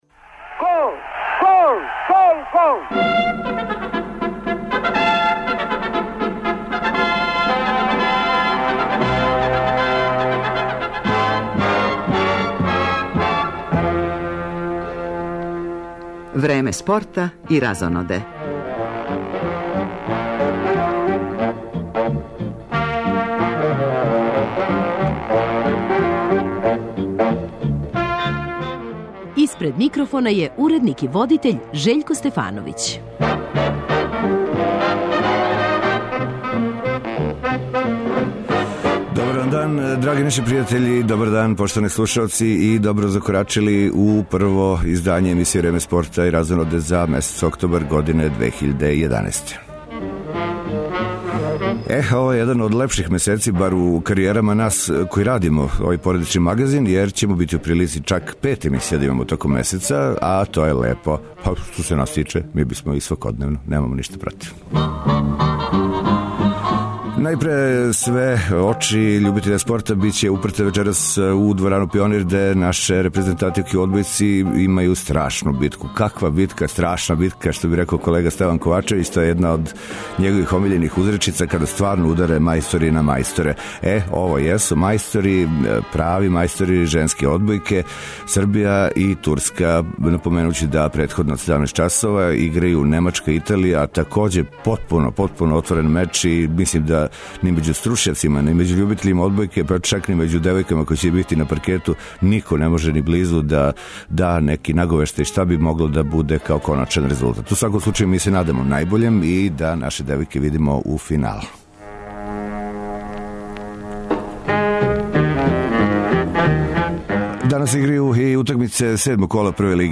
Тако овог пута, већ на самом почетку емисије, пратимо комбиноване преносе сусрета 7. кола овдашњег шампионата. Извештаваћемо и о кретању резултата Прве лиге Србије, као и немачке Бундес, односно енглеске Премијер лиге.